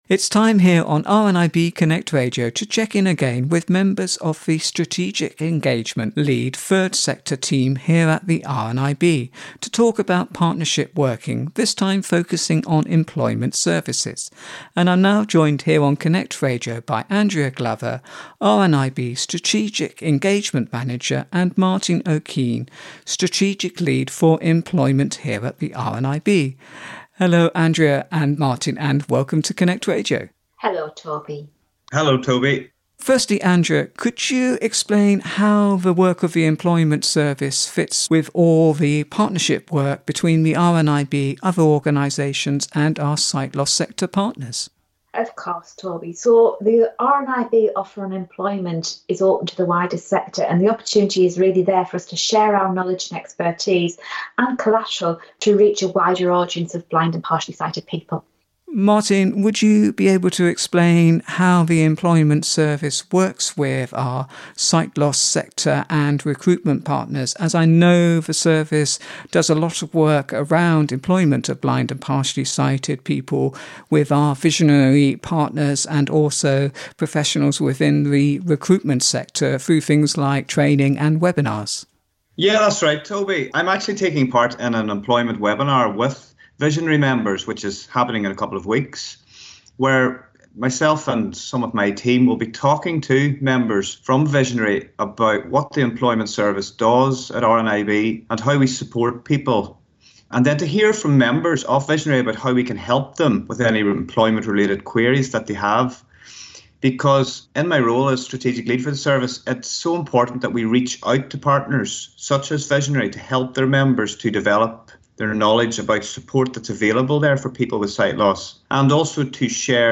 In the next feature of our new series of interviews looking at how the RNIB is working in partnership with sight loss sector partners and other organisations to make a more inclusive and accessible society for blind and partially sighted people